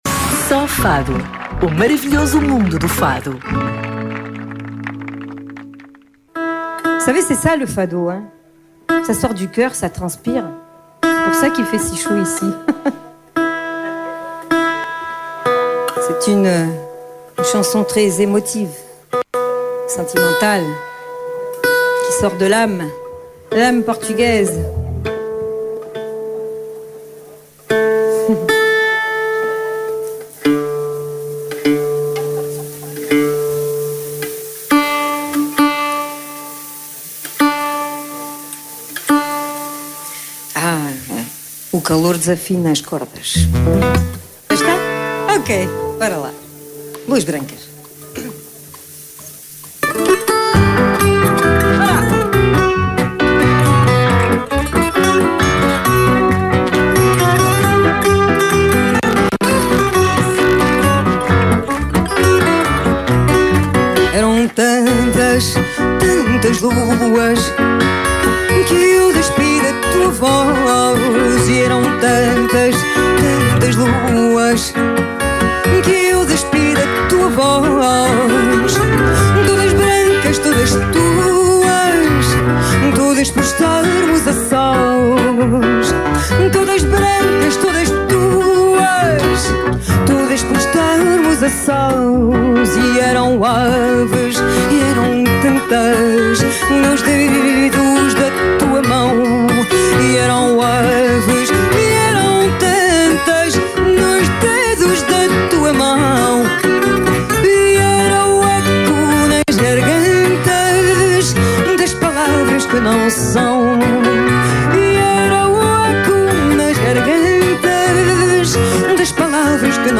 Emissão especial ao vivo na Sala Vasco da Gama